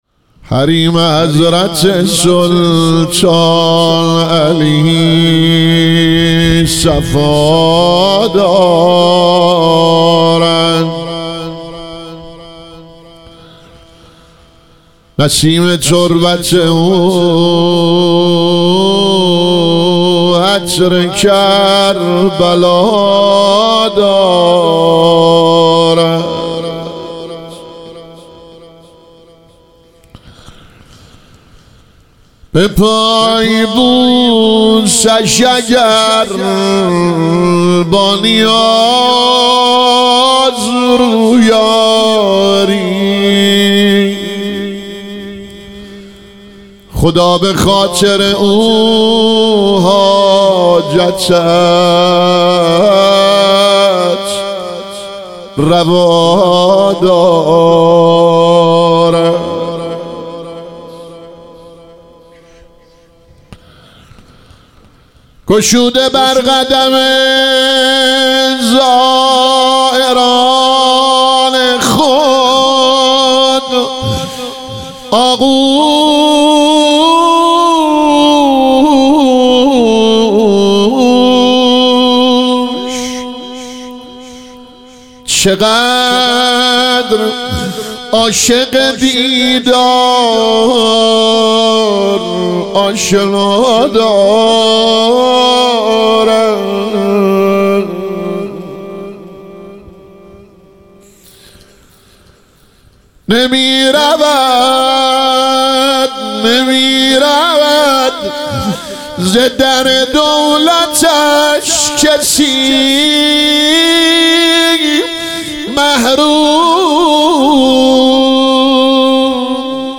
شهادت حضرت سلطانعلی علیه السلام - روضه